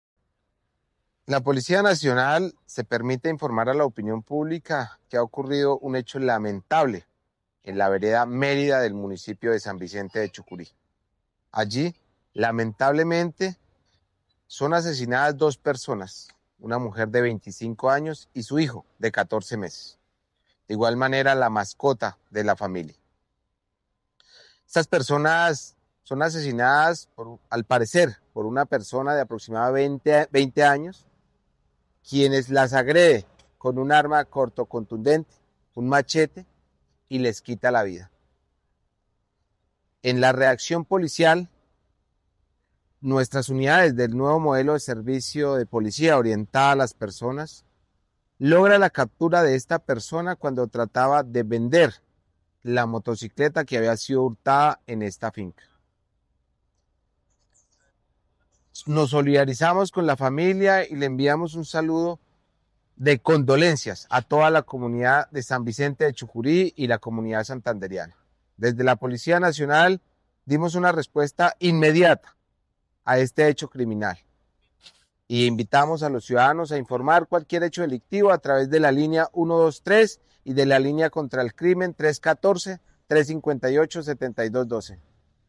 Coronel Néstor Rodrigo Arévalo Montenegro Comandante Departamento de Policía Santander